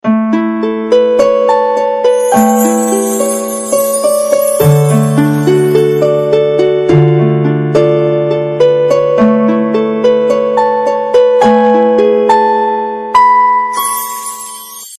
• Качество: 128, Stereo
нежные
Классное нежное смс